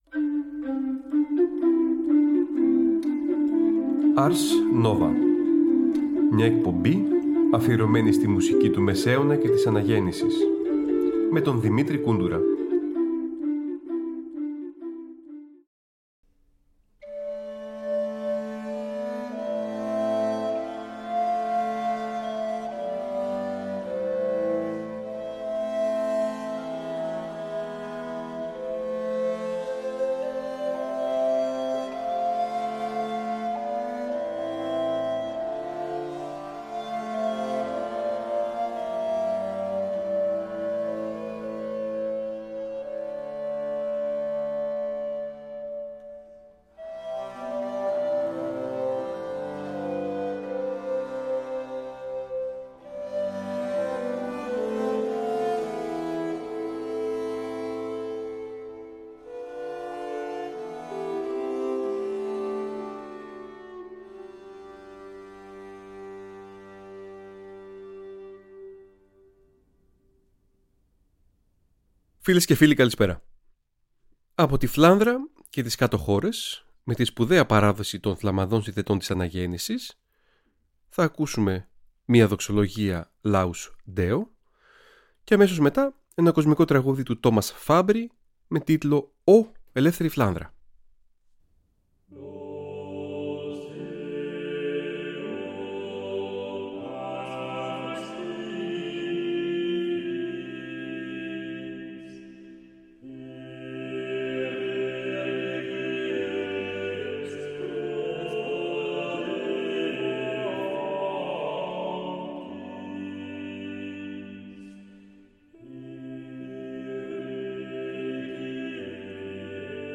Έργα των Ίζαακ, Όκεγκεμ, Ζοσκέν
Αναγεννησιακη Μουσικη